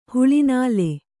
♪ huḷi nāle